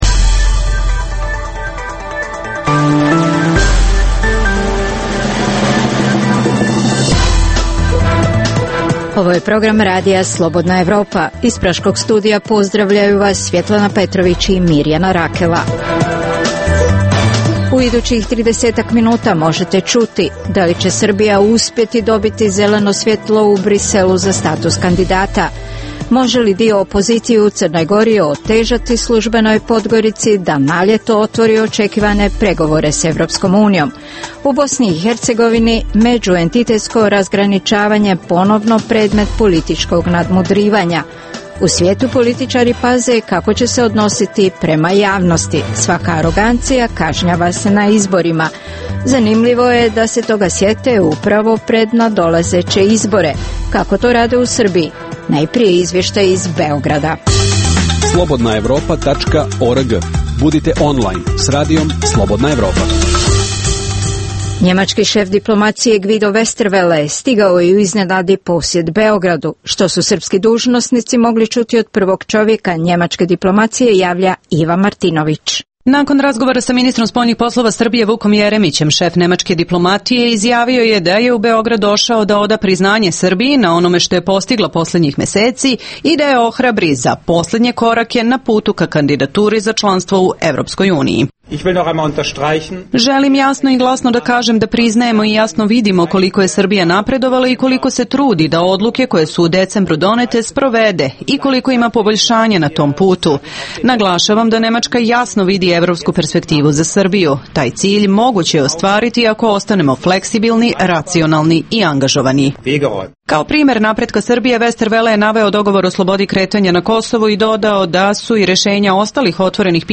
Aktuelno: - Da li će Srbija uspjeti dobiti zeleno svjetlo u Bruxellesu za status kandidata - Može li dio opozicije u Crnoj Gori otežati službenoj Podgorici da na ljeto otvori očekivane pregovore s Evropskom unijom - U Bosni i Hercegovini međuentitetsko razgraničavanje ponovno predmet političkog nadmudrivanja. Dokumenti dana: - Gost Radija Slobodna Evropa je poglavar Islamske vjerske zajednice Mustafa Cerić.